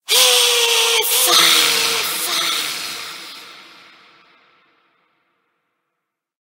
Cri d'Évoli Gigamax dans Pokémon HOME.